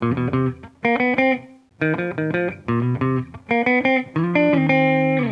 Index of /musicradar/sampled-funk-soul-samples/90bpm/Guitar
SSF_TeleGuitarProc1_90C.wav